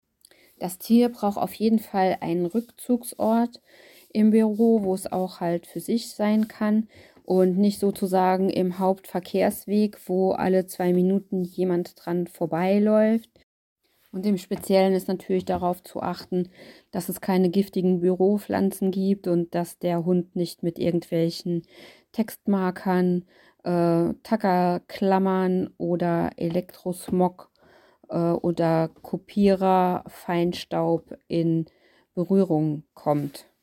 Sie können auch nur Experteninterviews aus ihrem Sendegebiet als Vorschlag erhalten.   jetzt abonieren